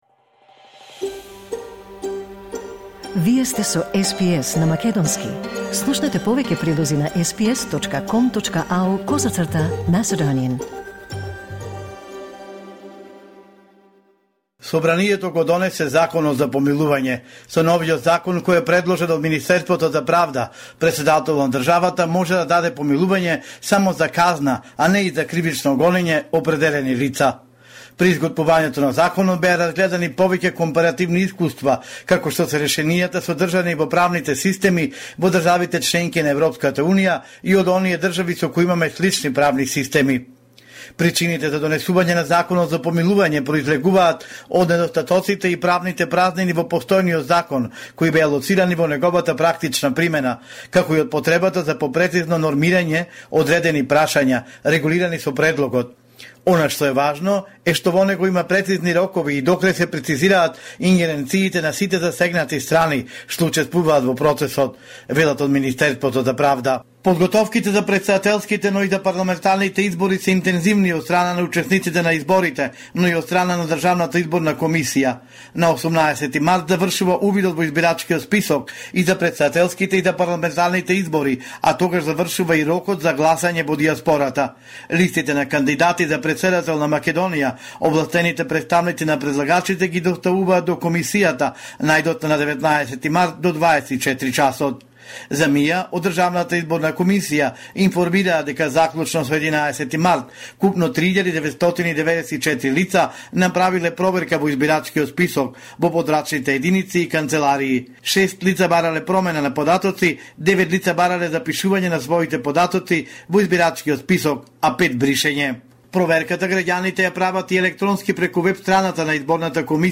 Homeland Report in Macedonian 14 March 2024